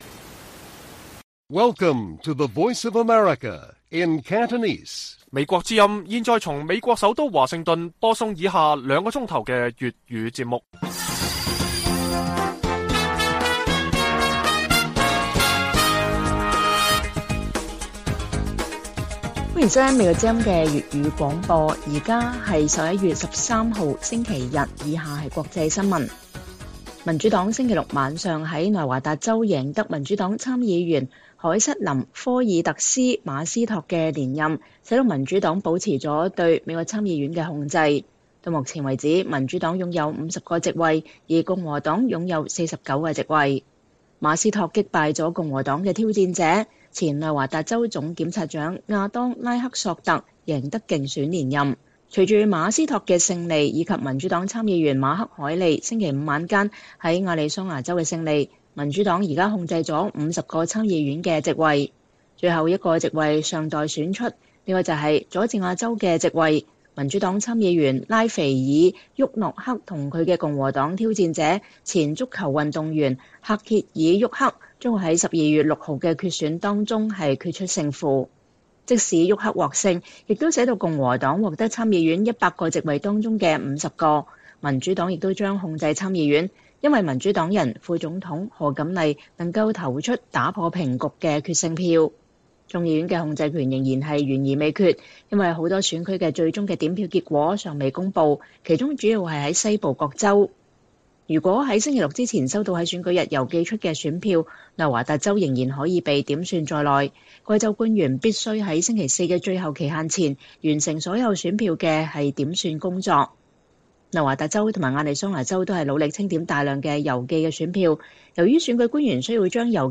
粵語新聞 晚上9-10點: 民主黨奪內華達州參議院席位 將繼續控制美國參議院